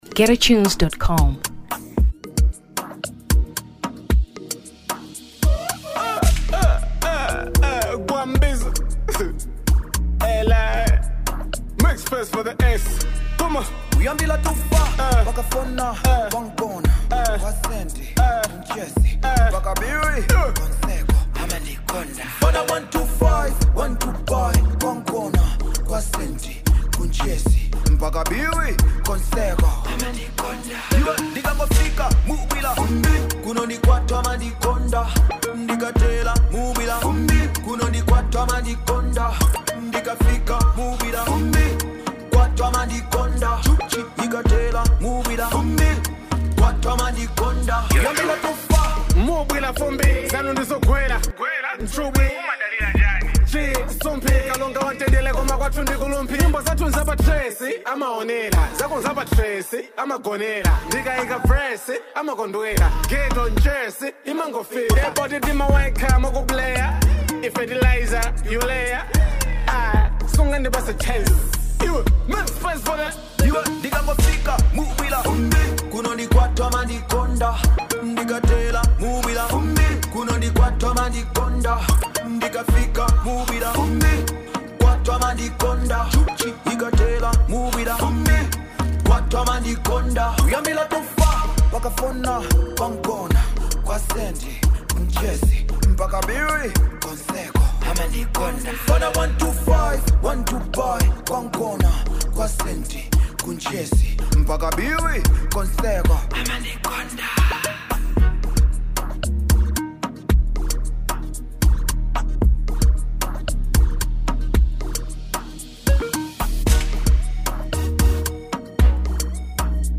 Afrobeat 2023 Malawi